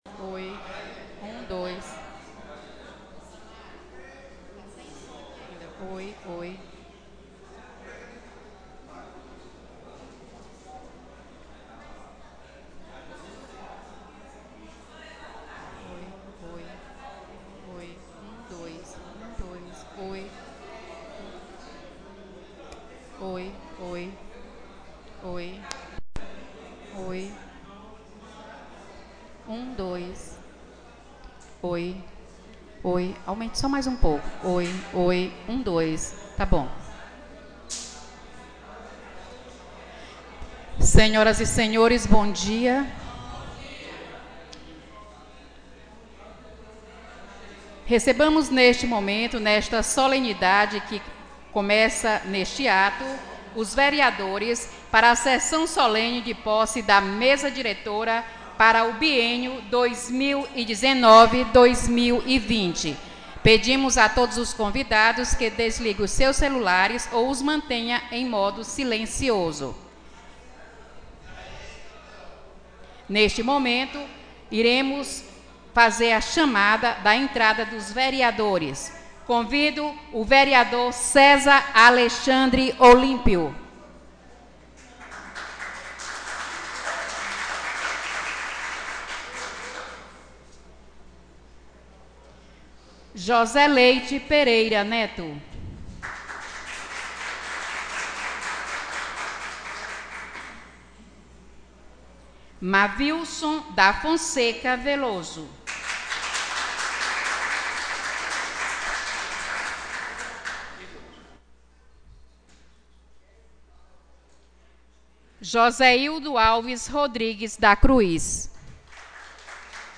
Sessão Solene de Posse da Mesa Diretora 2019/2020